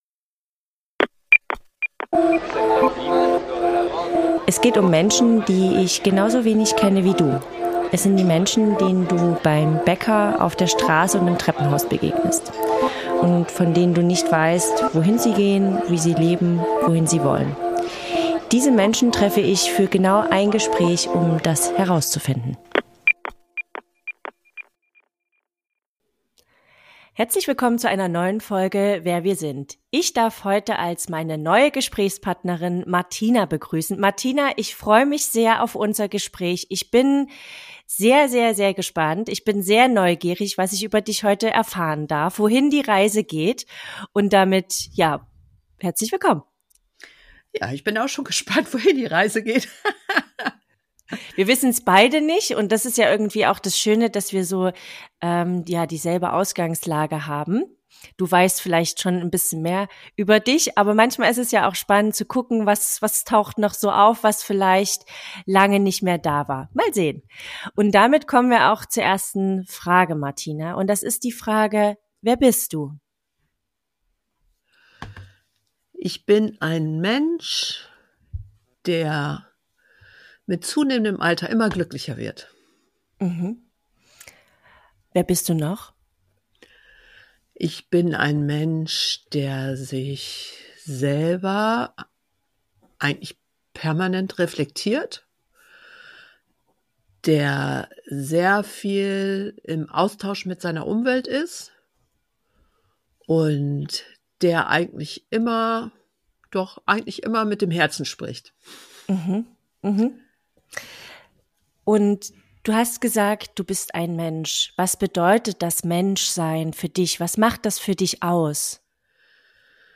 Ein Gespräch über Generationen hinweg, über Makel und Selbstbilder, über die Ambivalenz zwischen Beruf und Muttersein.